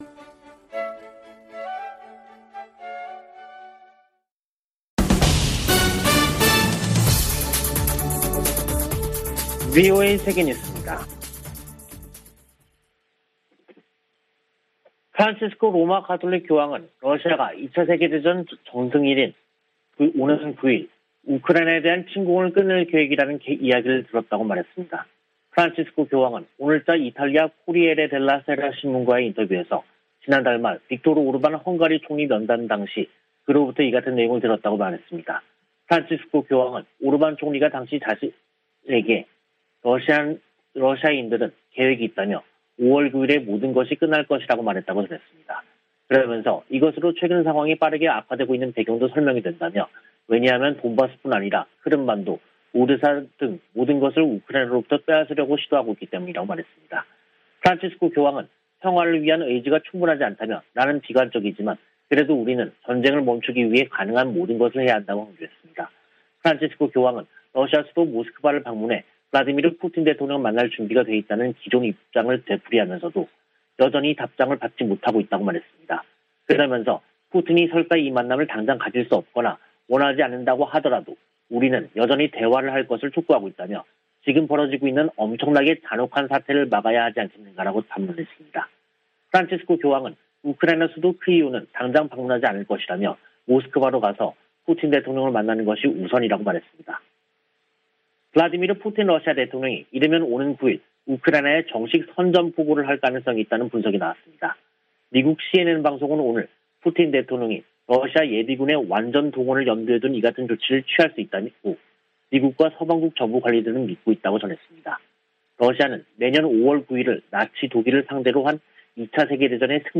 VOA 한국어 간판 뉴스 프로그램 '뉴스 투데이', 2022년 5월 3일 3부 방송입니다. 미 국무부는 북한 풍계리 핵실험장 복구 조짐에 대해 위험한 무기 프로그램에 대처할 것이라고 밝혔습니다. 한국의 대통령직 인수위원회는 북한 비핵화 추진을 국정과제로 명시했습니다. 한국과 중국의 북 핵 수석대표들은 북한의 핵실험 재개 움직임 등에 대해 상황 악화를 막기 위해 공동으로 노력하기로 다짐했습니다.